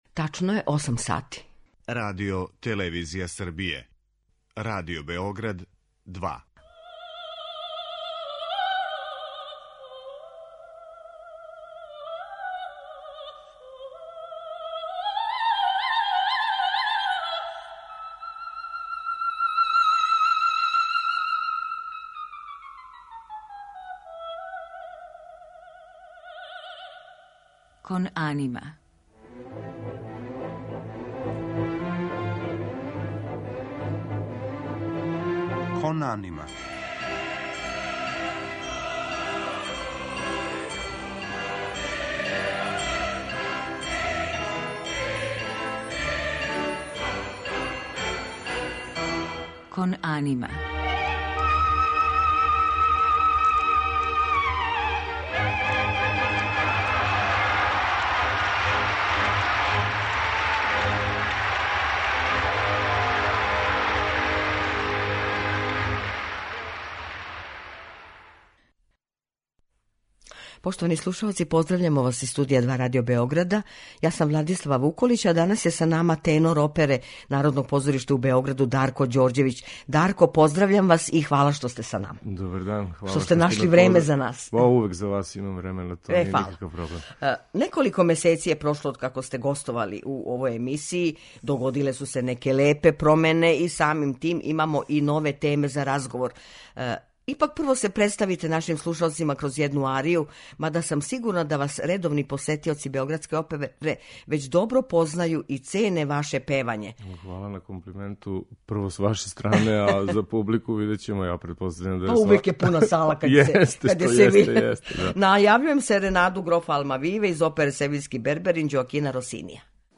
У музичком делу, биће емитовани фрагменти из Росиниjевог Севиљског берберина и Штраусове Саломе , у његовом тумачењу.